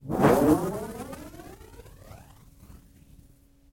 描述：在1/4"磁带上录制的磁带操纵的吱吱声、啾啾声和砰砰声，被切割后进行物理循环
标签： 啁啾 切好的 胶带环 胶带操纵 捶击
声道立体声